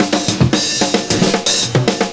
112FILLS05.wav